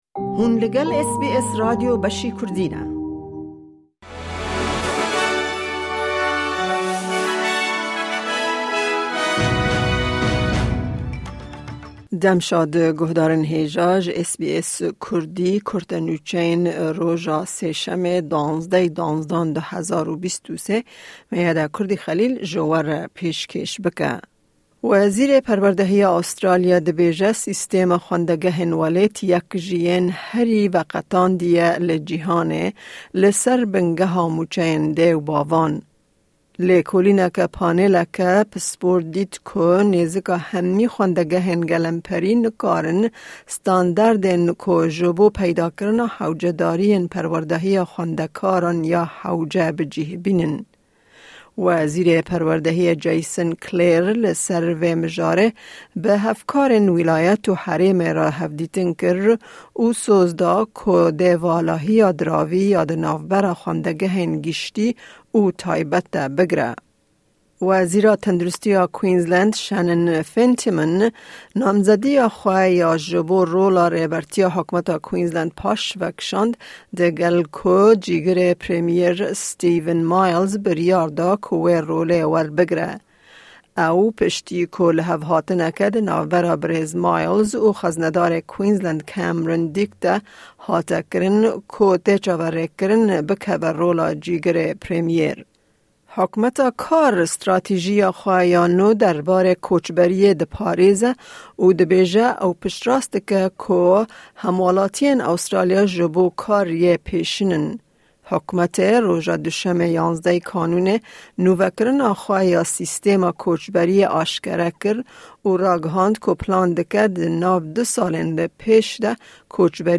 Newsflash